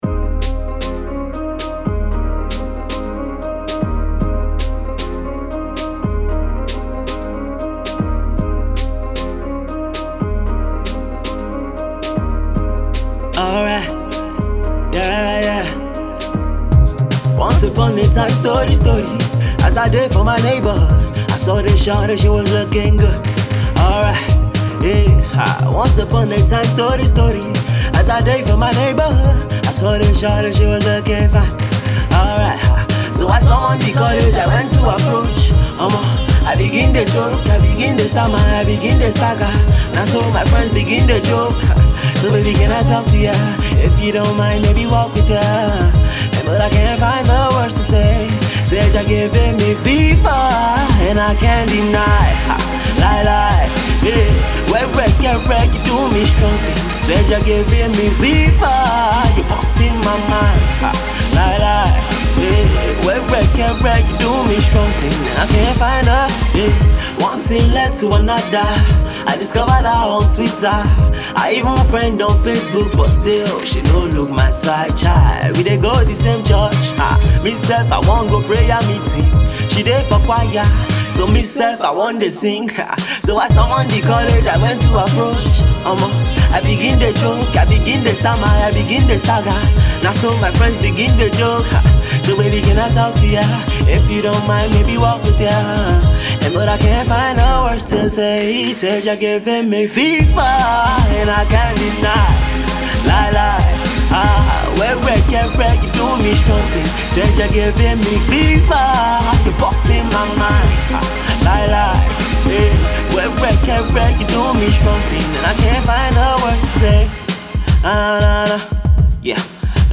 sung with great prowess and lyrics